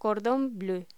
Locución: Cordon bleu
voz